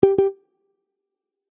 new-chat.oga